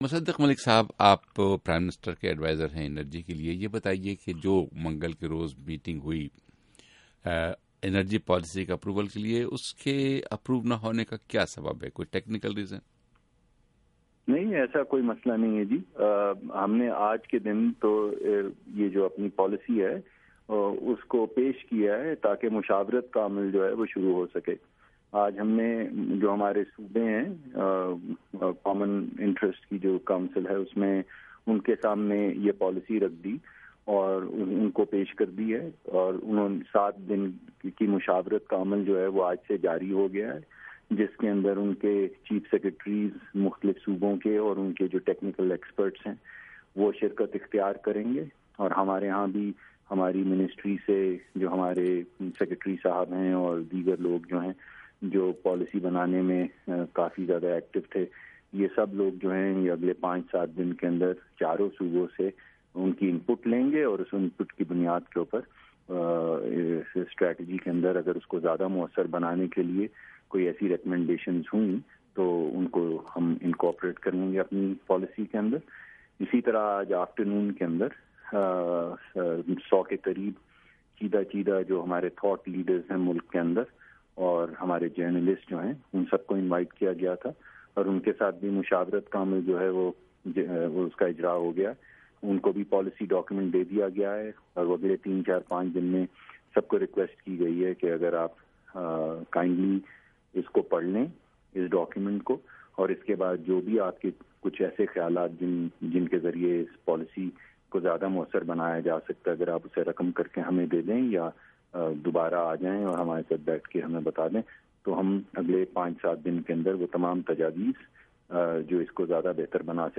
وزیر اعظم کے مشیر برائے توانائی، مصدق ملک کا انٹرویو